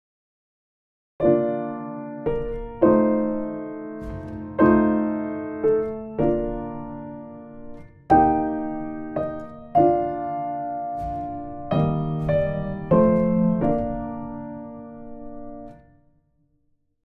7級B/変ロ長調３拍子
コードづけと伴奏形
1 左手をのばす